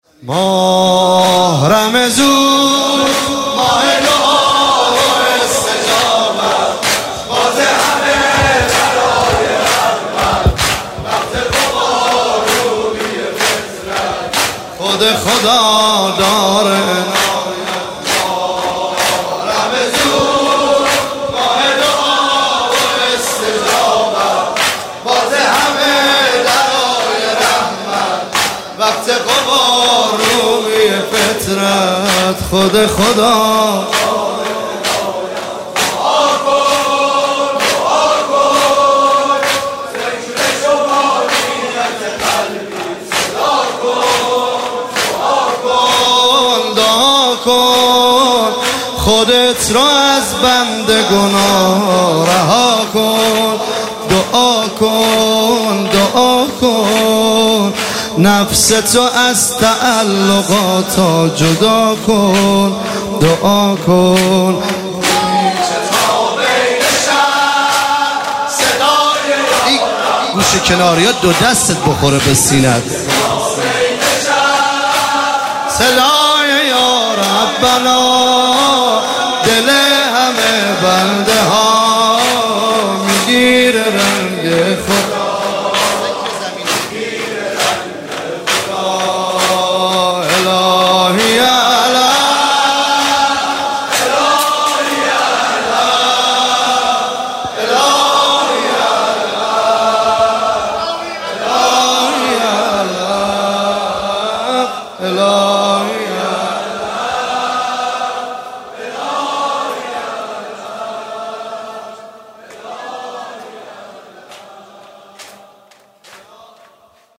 عنوان شب بیست و سوم ماه مبارک رمضان ۱۳۹۸
زمینه ماه رمضون ماه دعا و استجابت